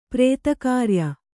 ♪ prēta kārya